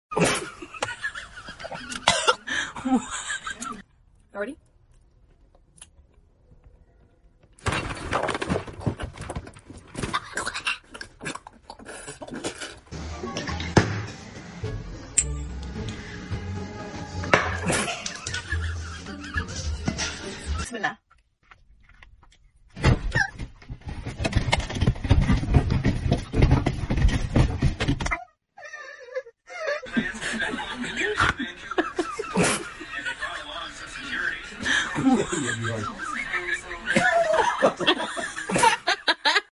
Ranking the Funniest Sour Taste Reaction